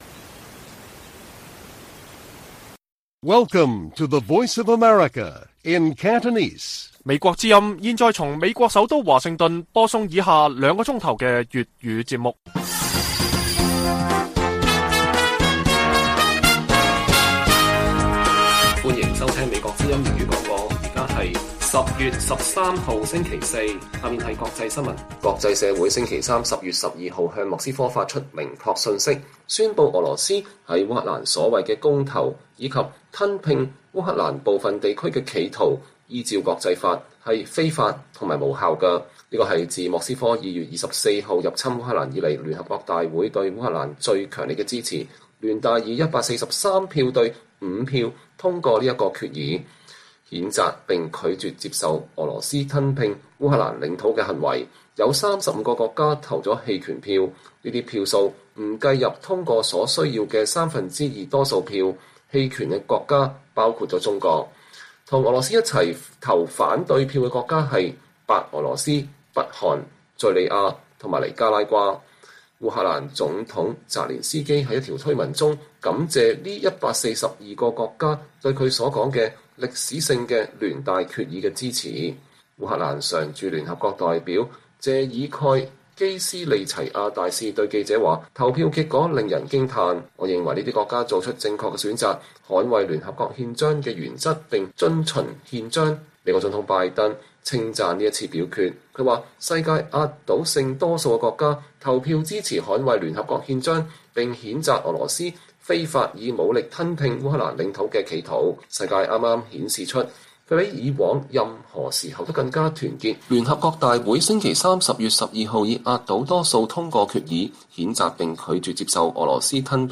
粵語新聞 晚上9-10點: 中國官媒低調處理北京在聯大通過決議譴責俄羅斯吞併烏領土當中投棄權票